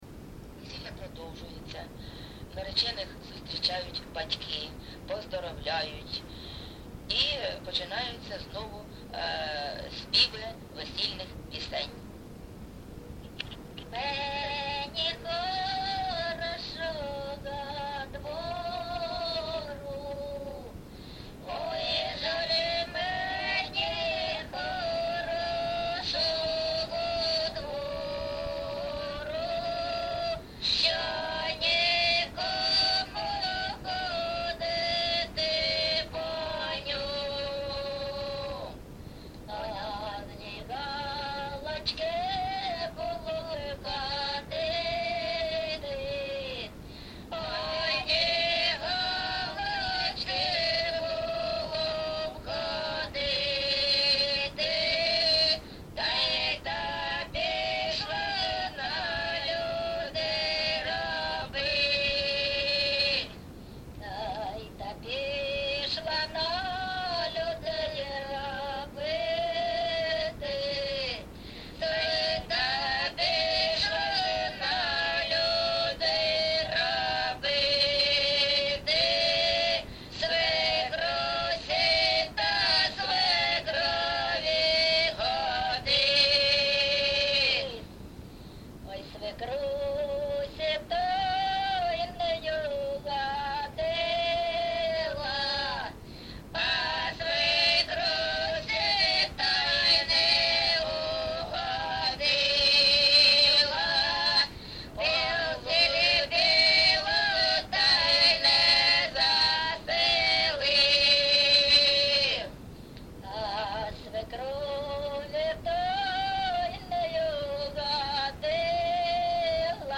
ЖанрВесільні
Місце записус. Некременне, Олександрівський (Краматорський) район, Донецька обл., Україна, Слобожанщина